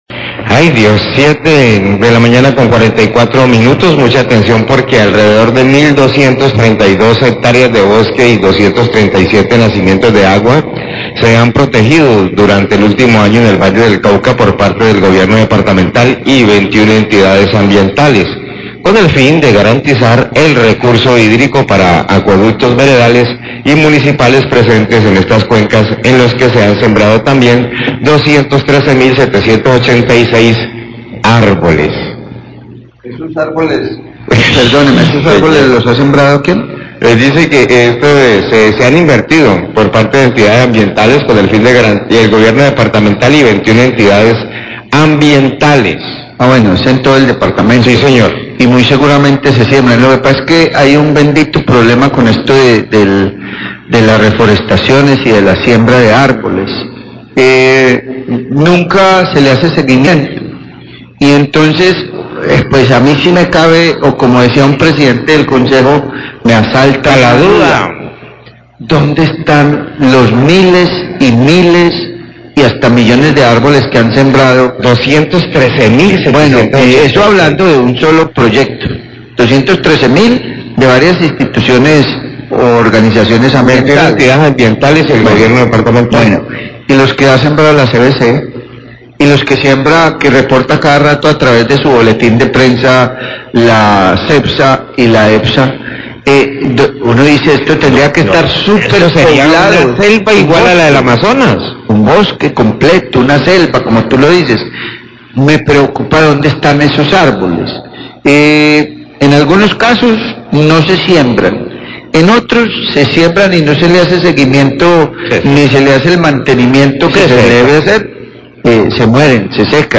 Radio
Los periodistas muestran su preocupación por el control de las cifras de las siembras de árboles por parte de la CVC y EPSA, señalan que no se ven esos árboles que anuncian en sus comunicados, que no se hacen ningún seguimiento para verificar que realmente se siembran.